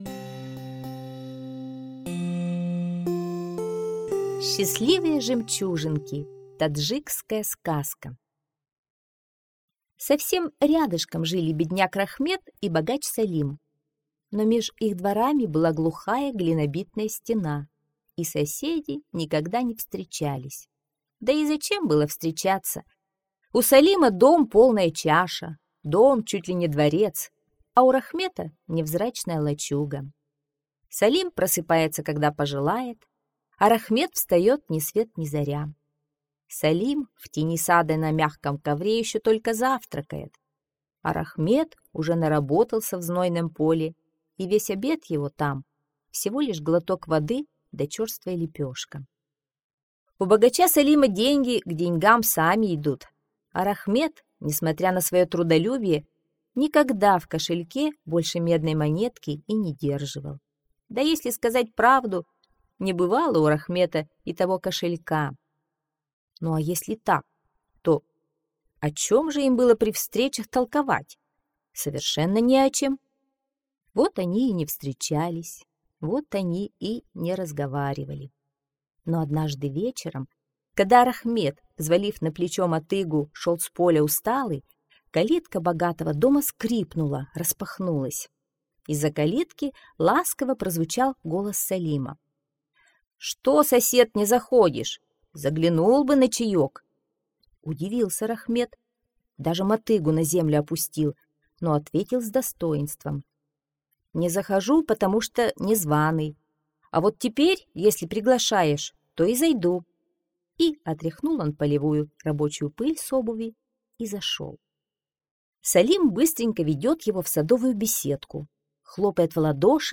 Счастливые жемчужинки - таджикская аудиосказка - слушать онлайн